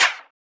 VEE Clap 016.wav